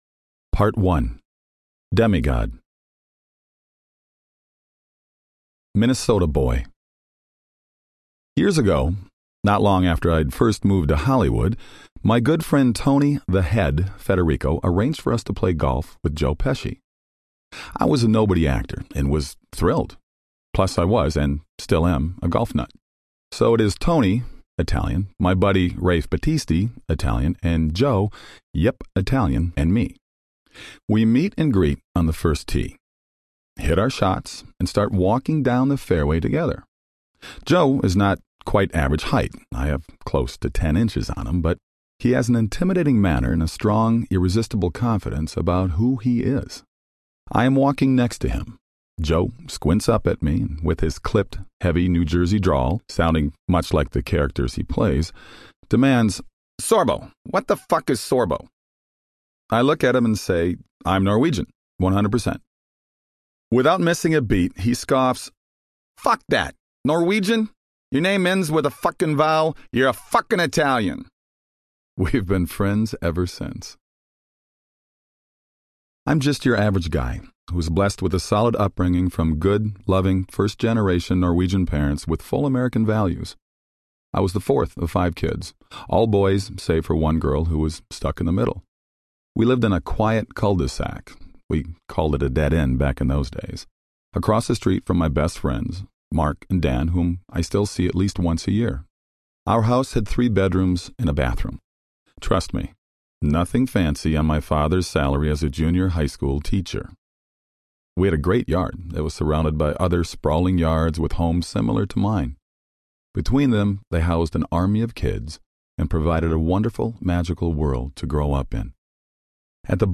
True Strength Audiobook
Narrator
Kevin Sorbo & Sam Sorbo